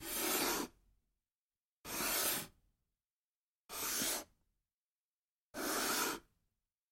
Шум фигур при перемещении по шахматной доске